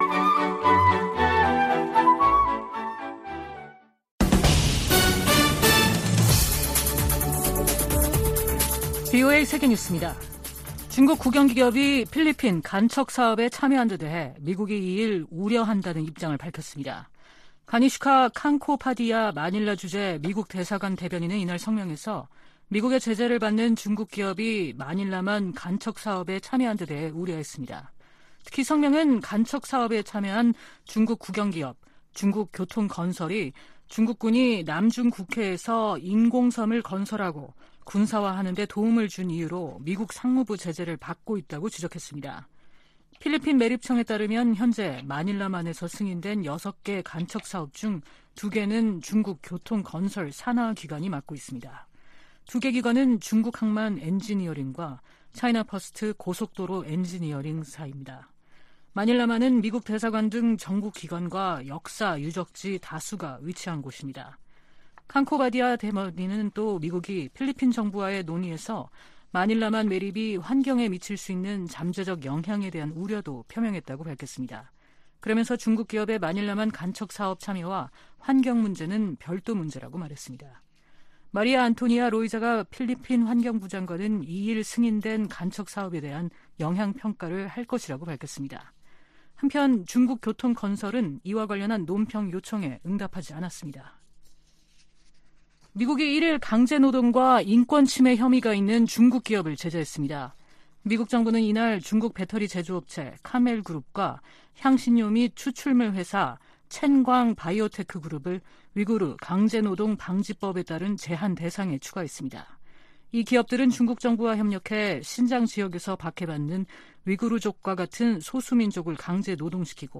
VOA 한국어 아침 뉴스 프로그램 '워싱턴 뉴스 광장' 2023년 8월 3일 방송입니다. 미국은 중국과 러시아가 북한의 행동을 규탄하는 데 동참할 수 있도록 계속 노력할 것이라고 린다 토머스-그린필드 유엔주재 미국대사가 밝혔습니다. 유럽연합(EU)이 북한과 러시아 간 무기 거래의 불법성을 지적하며 중단을 촉구했습니다. 미국 국방부가 미한일 3자 안보 협력 확대를 위해 노력하겠다는 입장을 거듭 확인했습니다.